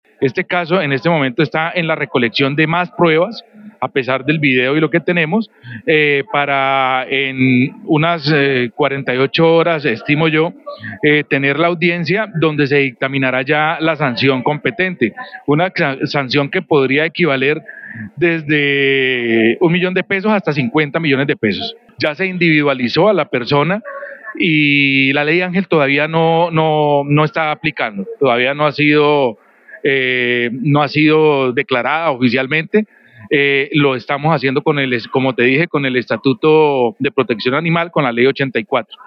Gildardo Rayo, secretario del Interior de Bucaramanga